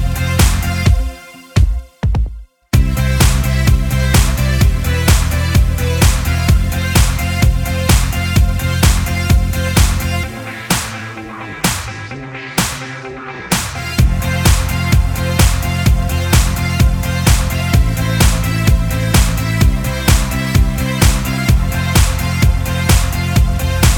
no Backing Vocals Dance 3:53 Buy £1.50